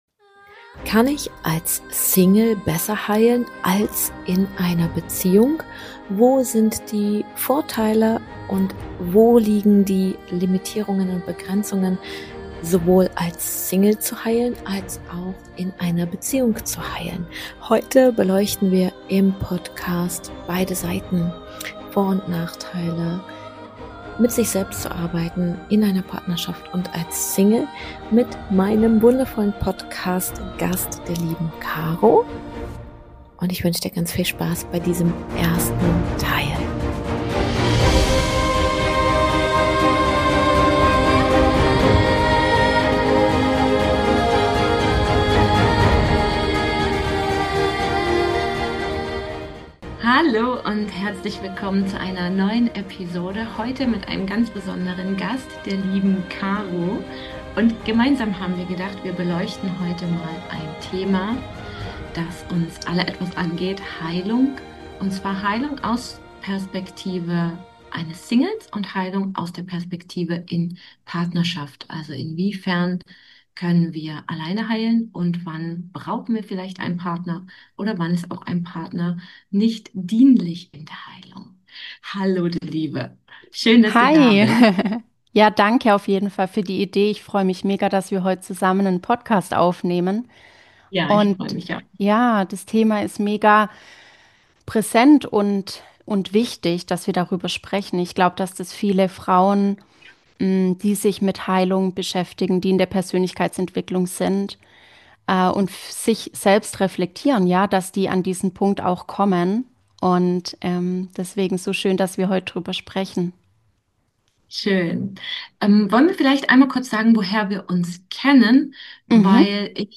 Was passiert, wenn wir unsere Wunden in der Stille des Alleinseins heilen und was, wenn Beziehung zur Bühne wird, auf der alte Verletzungen sichtbar werden und gleichzeitig neue Heilräume entstehen können? Ein ehrliches Gespräch aus zwei Perspektiven.